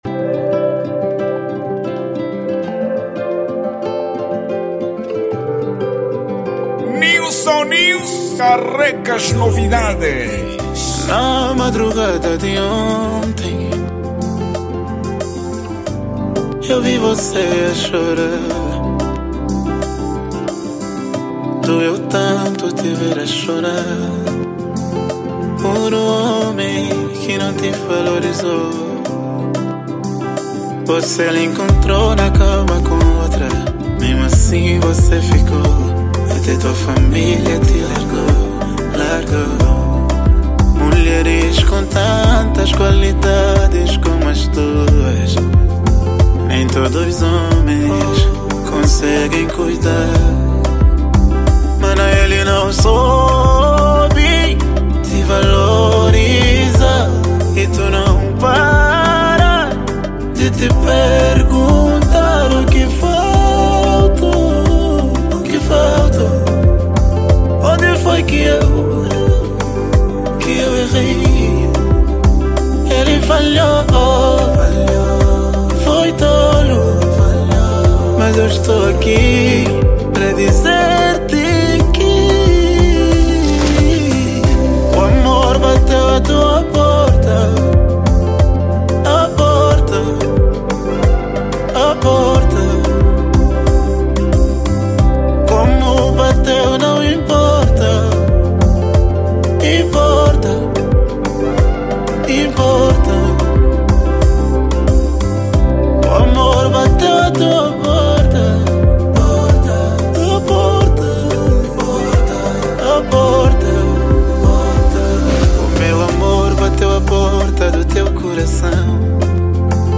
| Afro Music